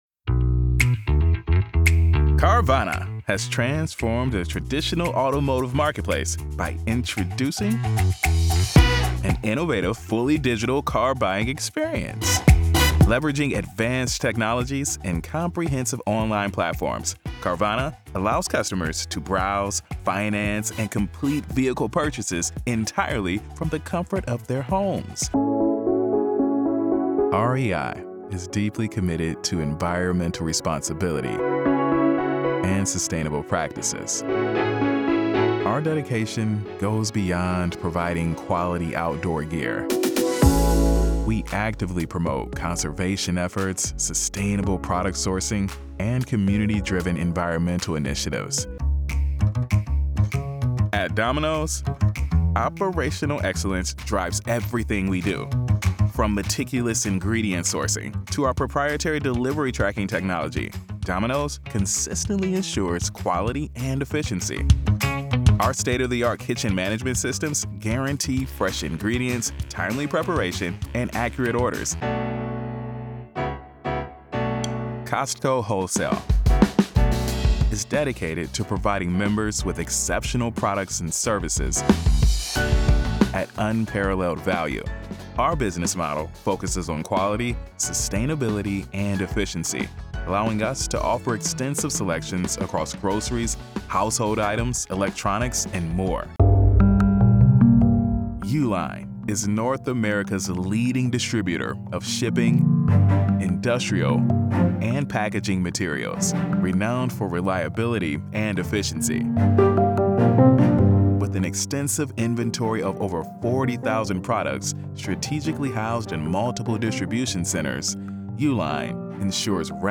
Corporate Narration - Upbeat, Real, Passionate
From his home studio he narrates voiceovers with an articulate, genuine, conversational, and authentic feel.